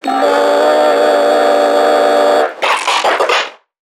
NPC_Creatures_Vocalisations_Infected [72].wav